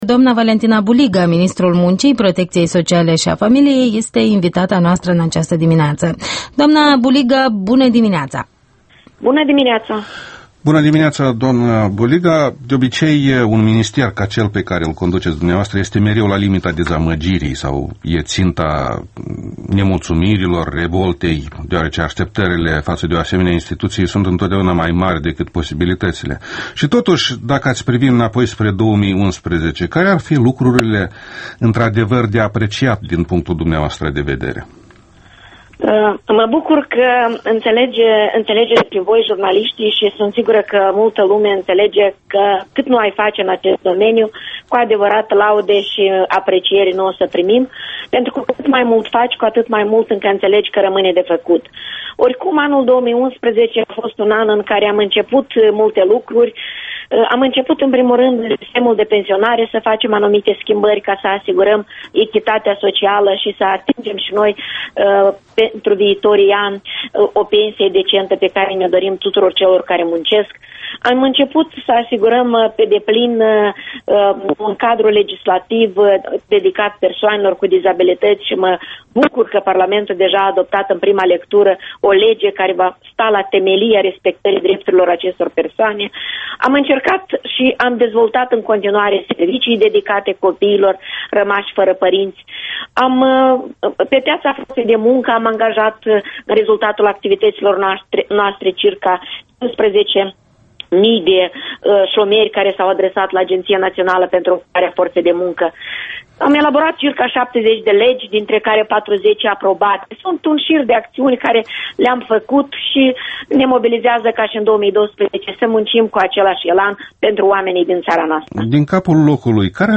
Interviul dimineții la Europa Liberă: cu ministrul muncii Valentina Buliga